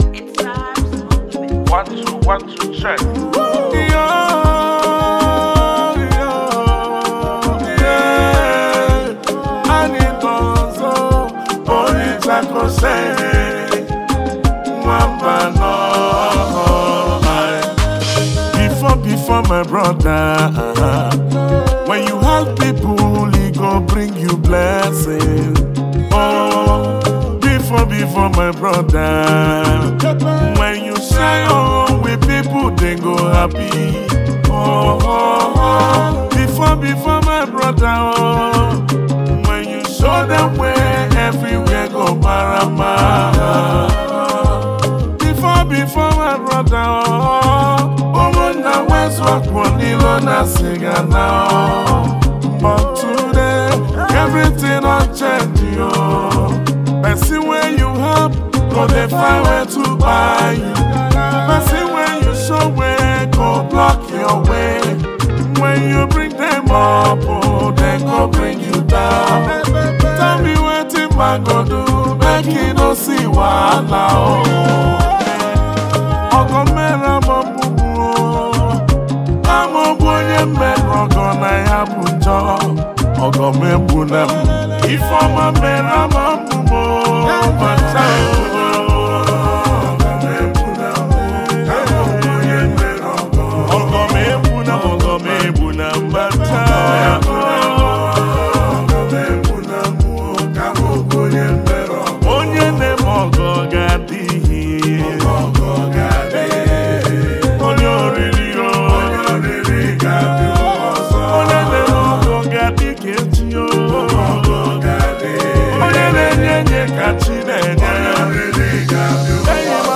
Heavyweight Igbo Music and Highlife